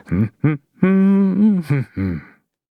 Kibera-Vox_Hum_b.wav